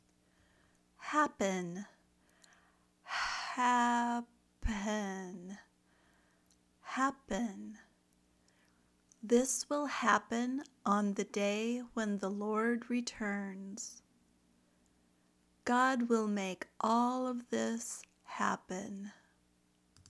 /ˈ pən/ (verb)